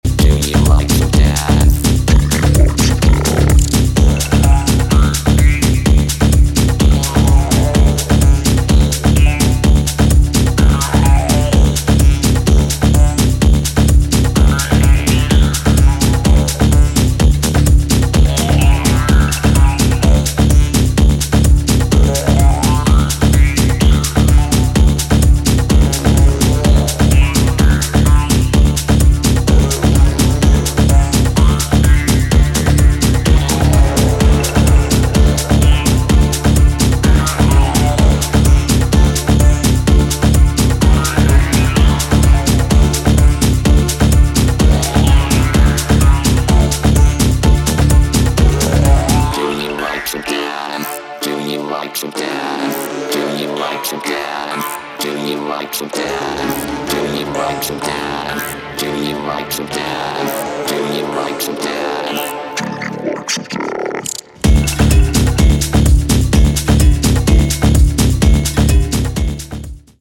4/4の暗黒トライバル・グルーヴとヴォコーダー・ヴォイスで現行ピークタイムを狙う